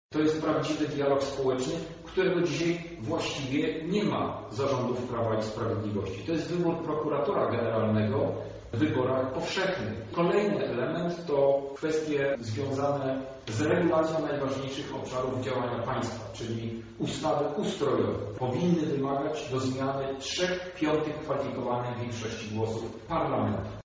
Więcej na ten temat mówi europoseł Krzysztof Hetman: